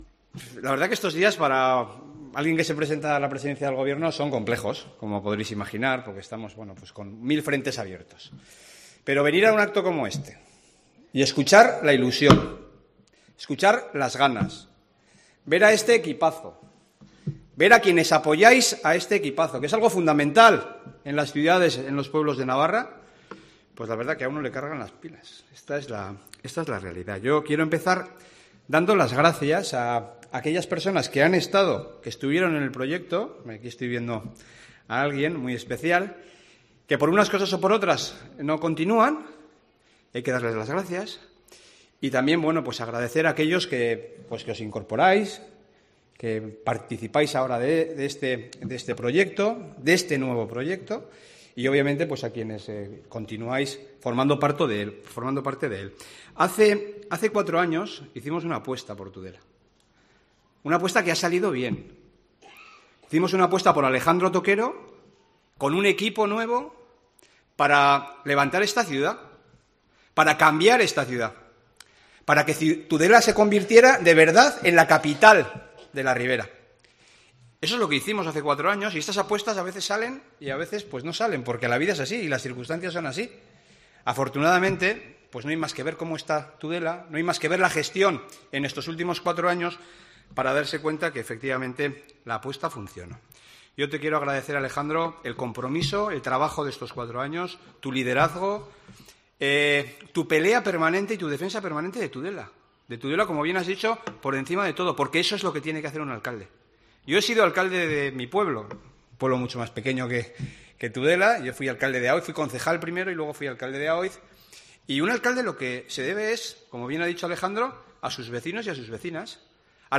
DISCURSO DE JAVIER ESPARZA EN LA PRESENTACIÓN DE CANDIDATURA ALEJANDRO TOQUERO